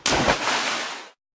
drop.ogg.mp3